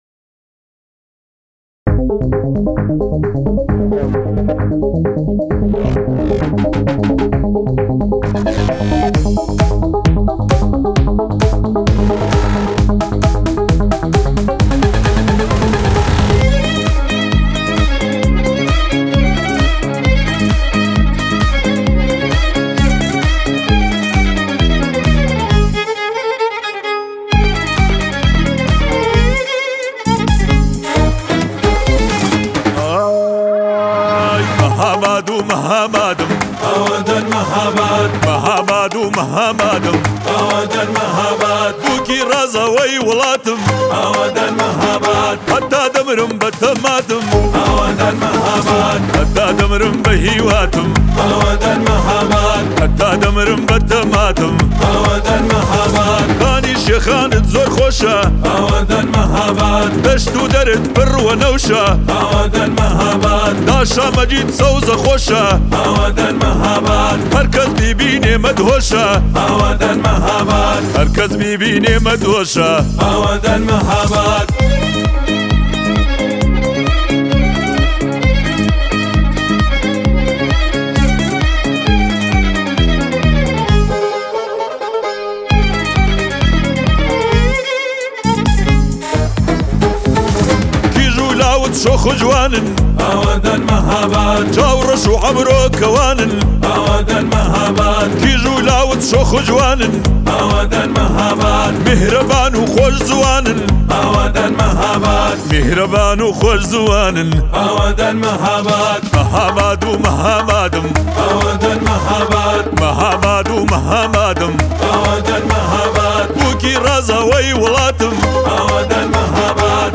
655 بازدید ۳ اسفند ۱۴۰۲ آهنگ , آهنگ کردی